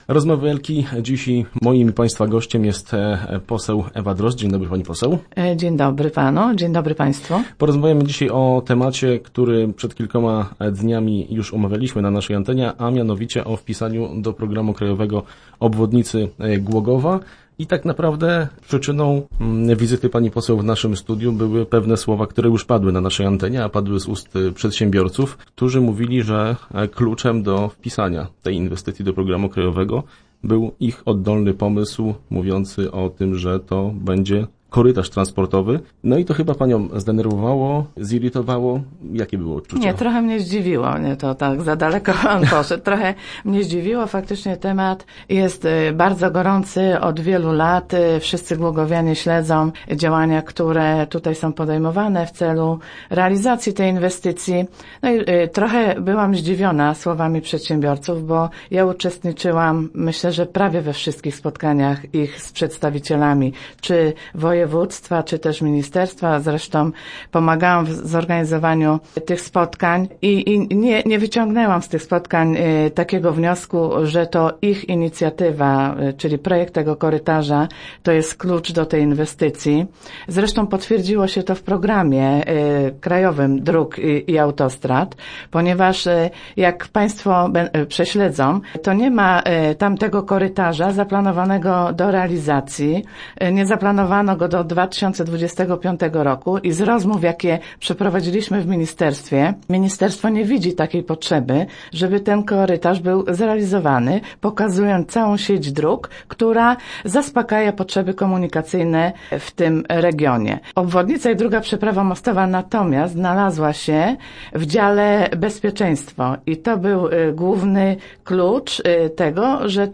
0903_ewa_drozd.jpgO obwodnicy oraz drugim moście w Głogowie, a także o programie krajowym na lata 2014-2023 rozmawialimy w czwartek w radiowym studiu z posłanką Platformy Obywatelskiej Ewą Drozd.